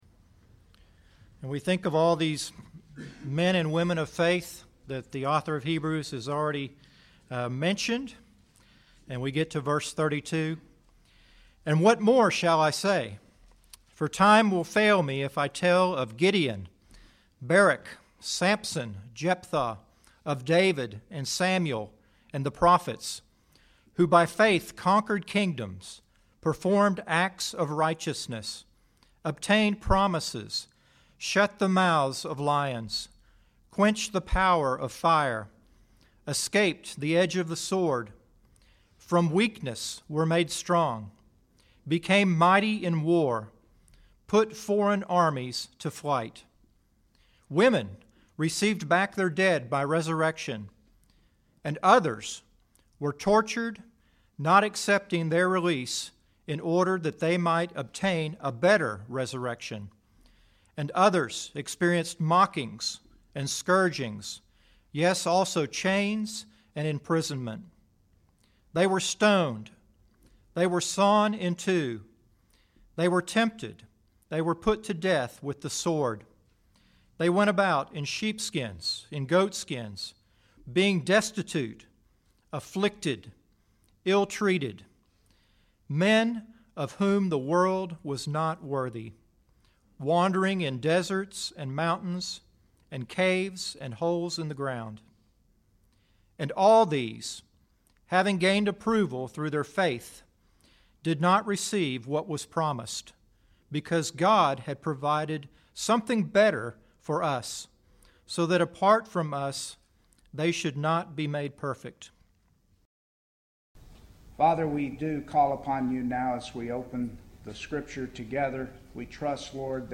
McCalla Bible Church Sermons